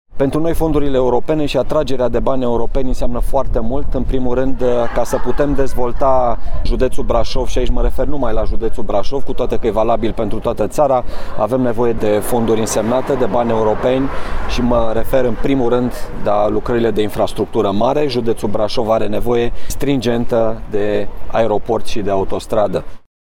Prefectul Judeţului Braşov, Marian Rasaliu: